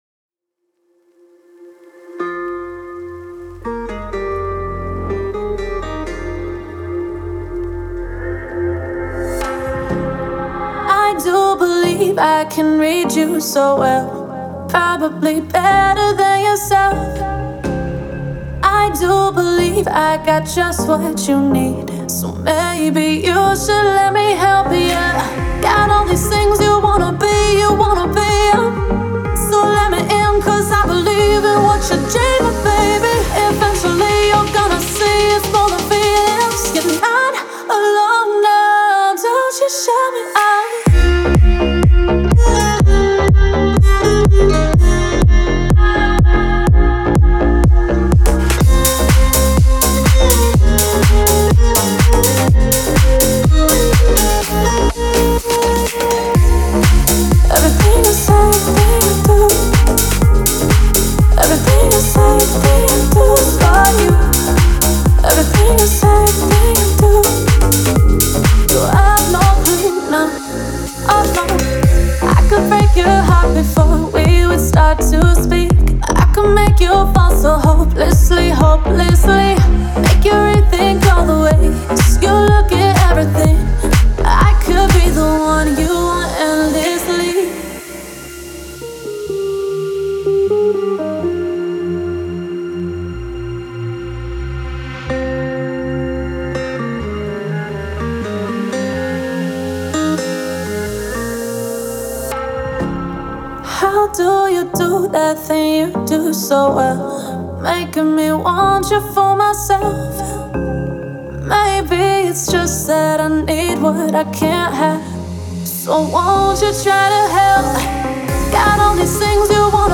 это захватывающий трек в жанре прогрессивного хауса